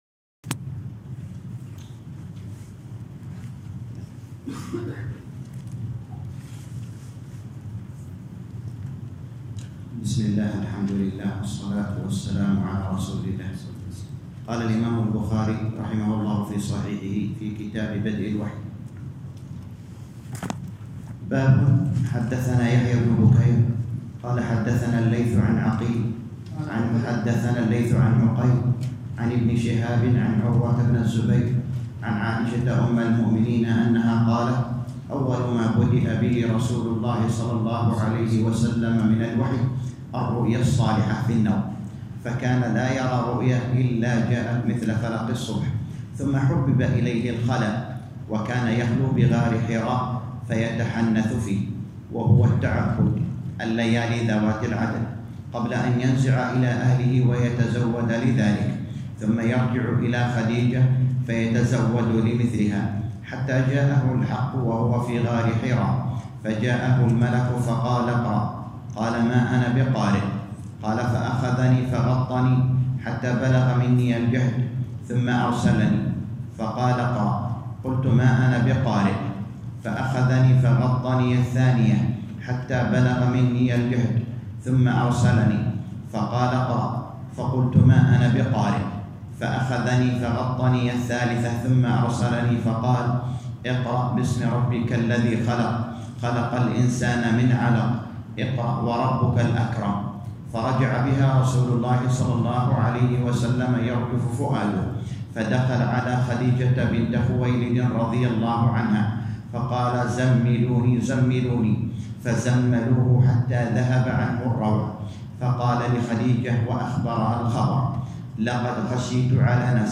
الدرس الثالث - شرح كتاب صحيح البخاري كتاب بدء الوحي _ 3